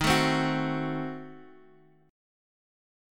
D#dim7 chord